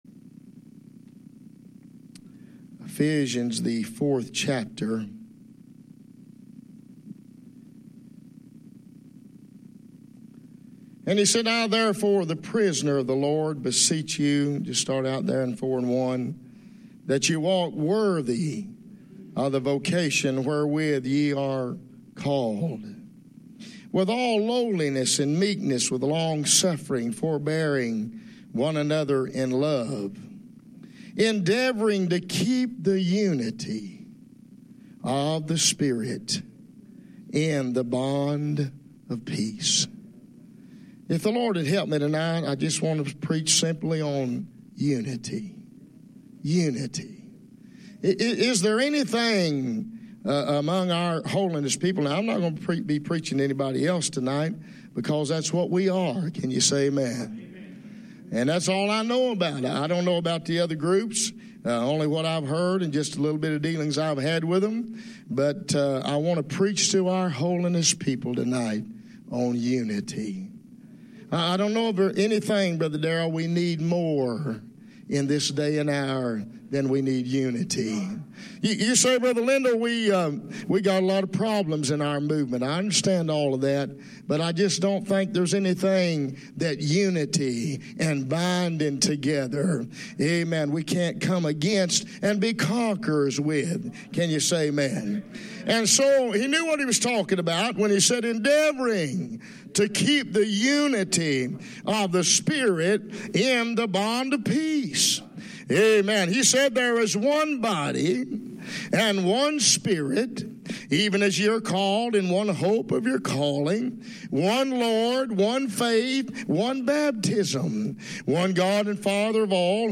2006 Unity - Southern Missouri Minister's Conference.mp3